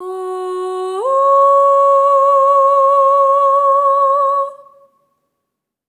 SOP5TH F#4-R.wav